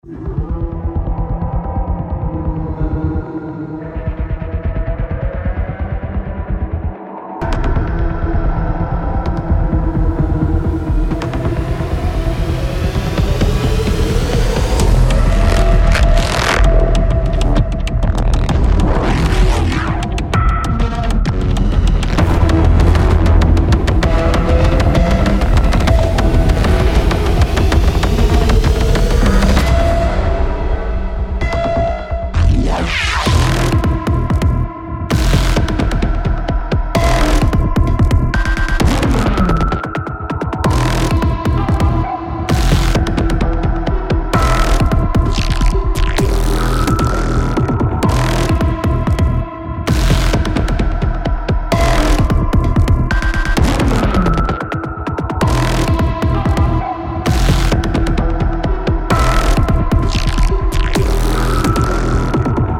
was a proof of concept, missing like half of the orchestral stuff and the choir but eeeeeehhhh
electronic cinematic doom style loud stuff